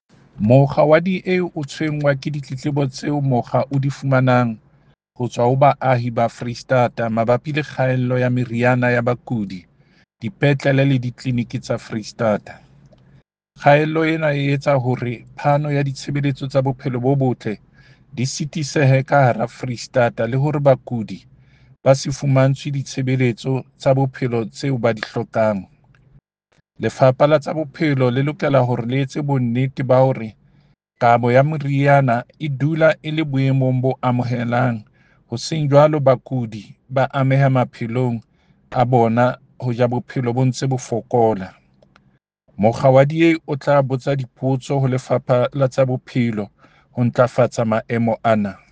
Sesotho soundbites by David Masoeu MPL and Afrikaans soundbite by Werner Pretorius MPL.